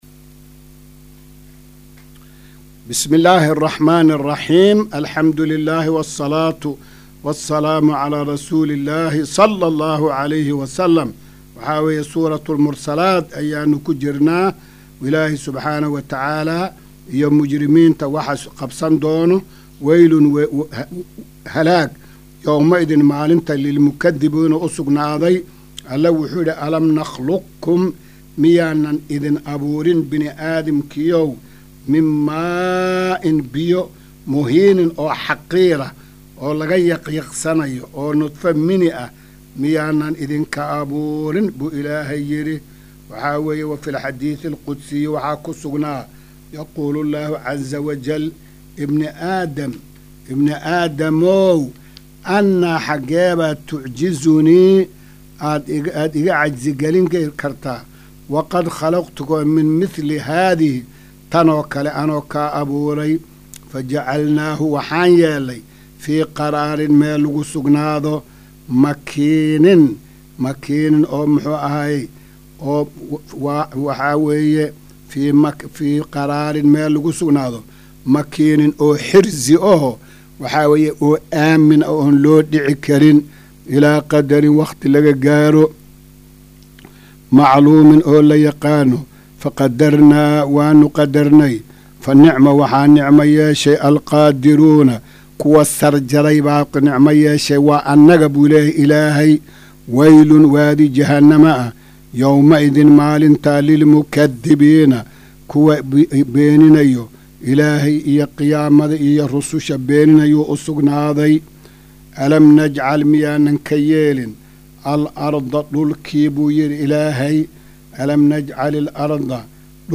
Maqal:- Casharka Tafsiirka Qur’aanka Idaacadda Himilo “Darsiga 278aad”